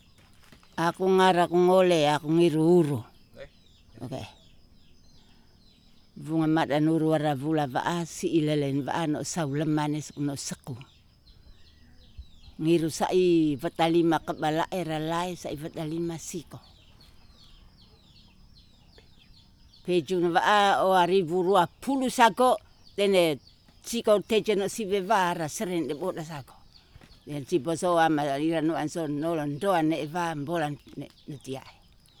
Speaker
Recording made in kampong Hoka, Ndeo.